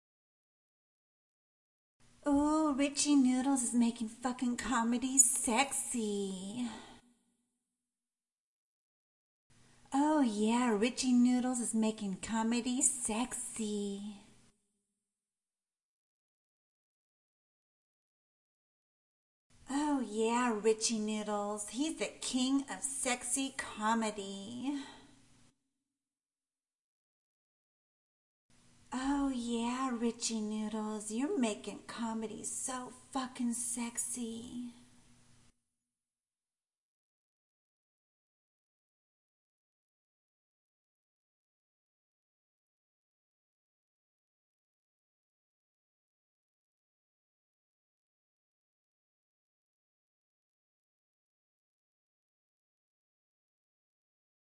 Tag: DJ-工具 DJ-降 女孩声乐